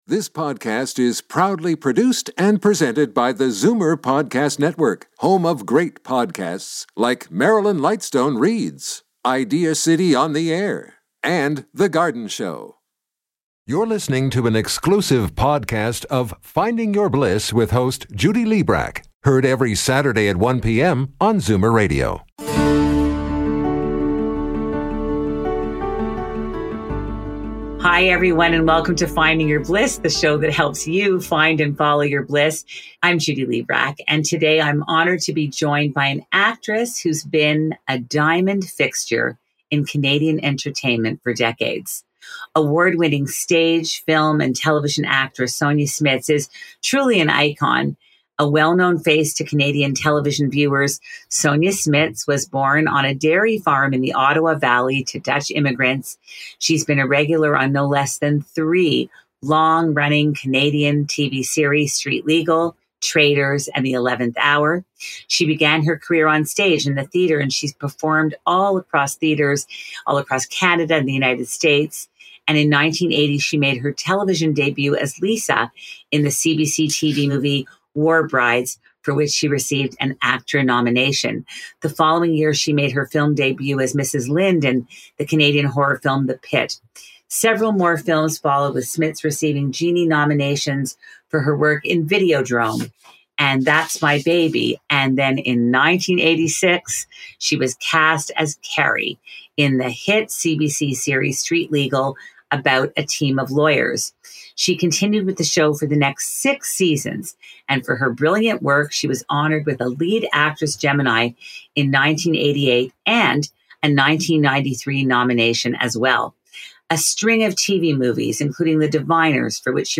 Join us every Saturday at 1 PM on Zoomer Radio.